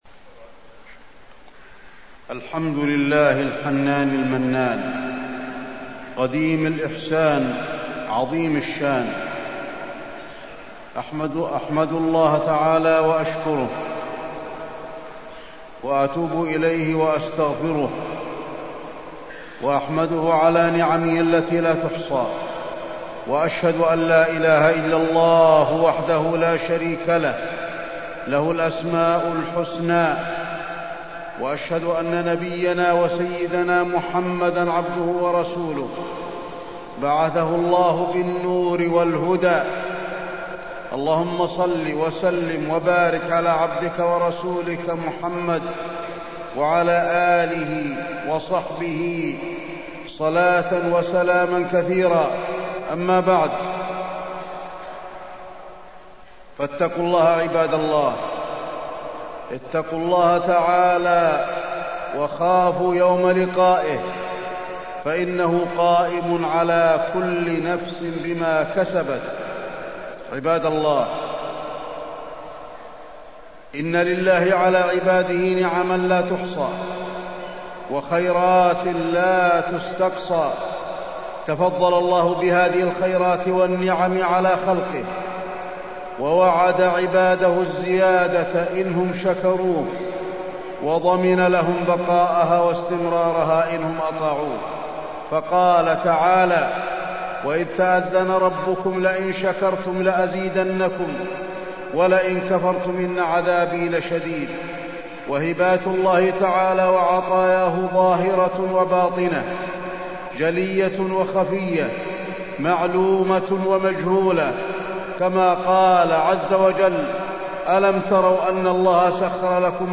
تاريخ النشر ١٠ ذو الحجة ١٤٢٥ هـ المكان: المسجد النبوي الشيخ: فضيلة الشيخ د. علي بن عبدالرحمن الحذيفي فضيلة الشيخ د. علي بن عبدالرحمن الحذيفي شكر النعم